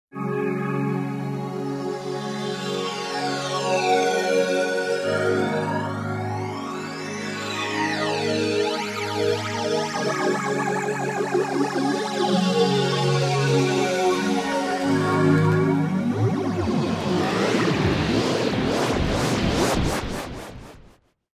Create anything from the classic tape flange to modern digital flanges with these beautifully flexible effects.
Each sequence is in a dry version and then effected in different ways.
Extreme Flanger.mp3